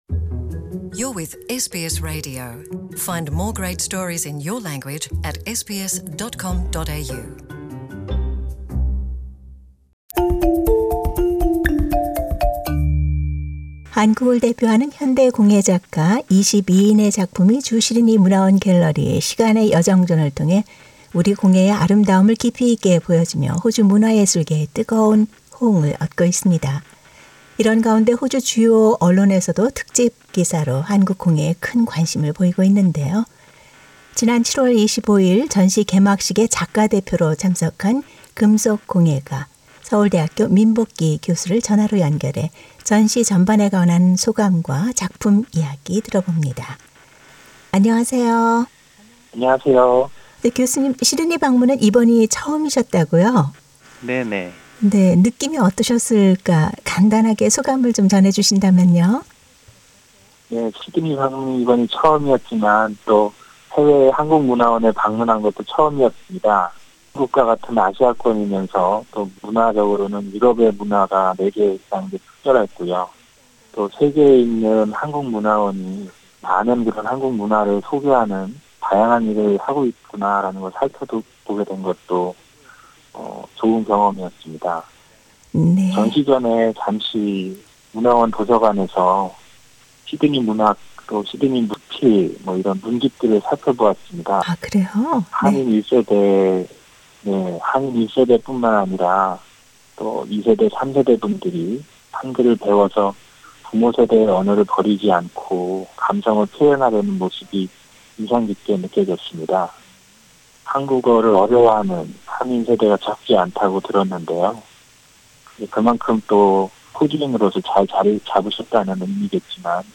전화 대담을 통해 전시 소감과 작품 내용, 한국 공예의 미래 등에 대해 들어본다.